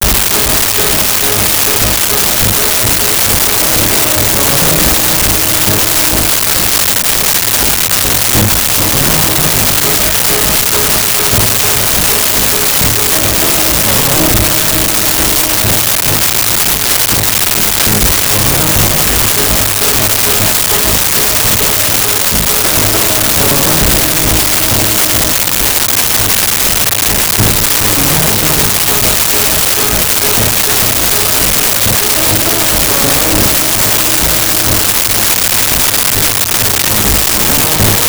Tension Loop
Tension_loop.wav